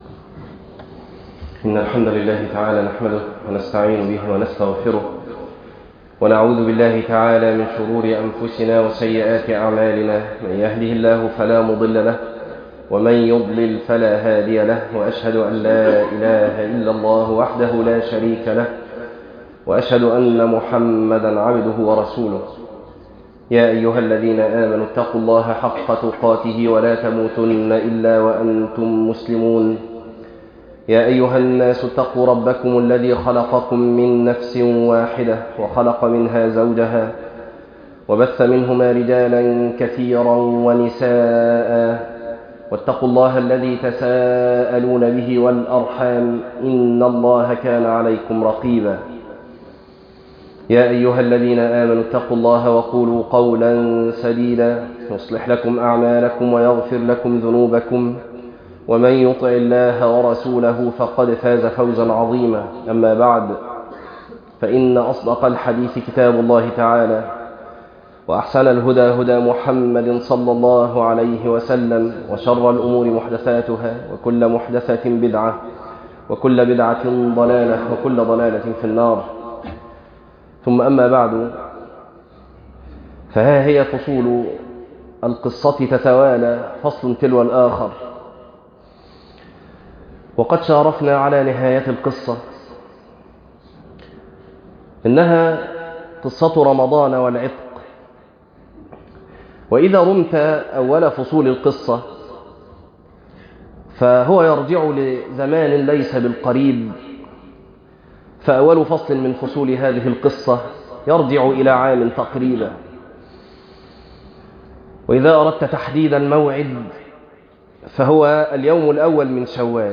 تفاصيل المادة عنوان المادة قصة رمضان والعتق - خطبة تاريخ التحميل الأثنين 13 ابريل 2026 مـ حجم المادة 11.00 ميجا بايت عدد الزيارات 26 زيارة عدد مرات الحفظ 13 مرة إستماع المادة حفظ المادة اضف تعليقك أرسل لصديق